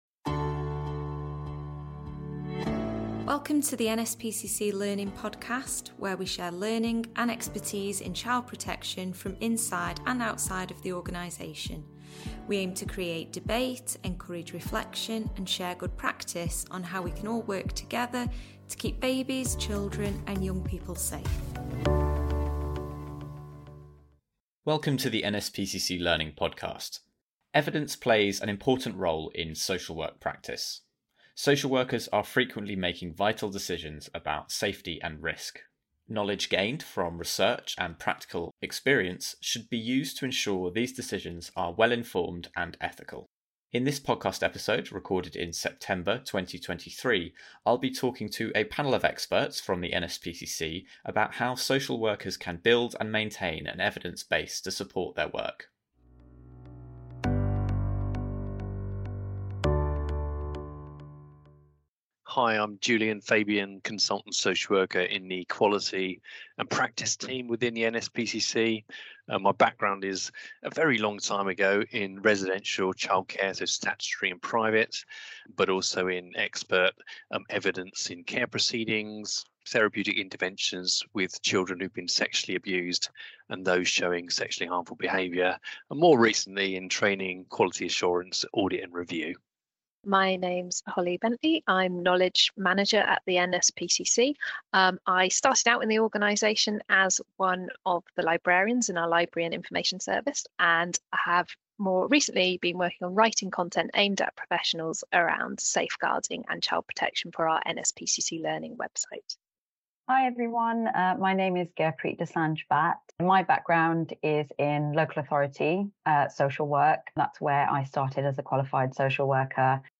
In this podcast episode, a panel of experts from the NSPCC discuss how social workers can build and maintain an evidence base to support their work.